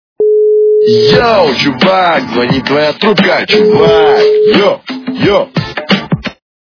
» Звуки » Смешные » Говорящий телефон - Йо, чувак, звонит твоя трубка
При прослушивании Говорящий телефон - Йо, чувак, звонит твоя трубка качество понижено и присутствуют гудки.